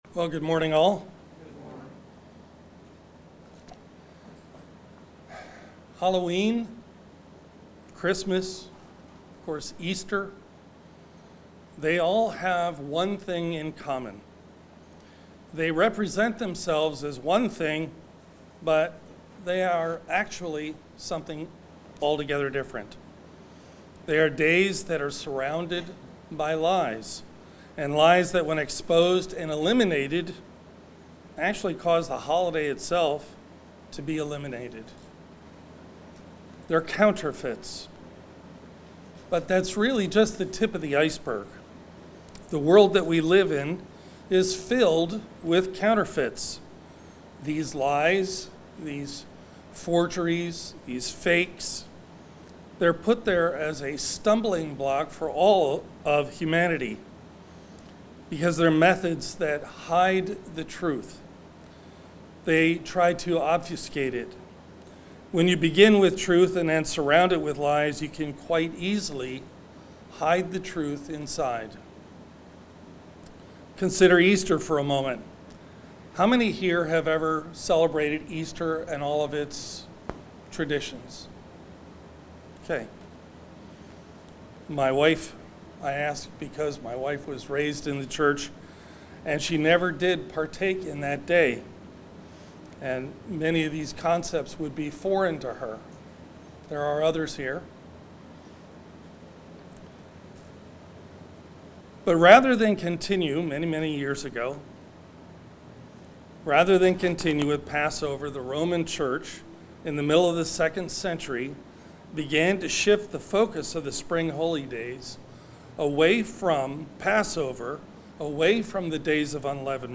Sermons
Given in Delmarva, DE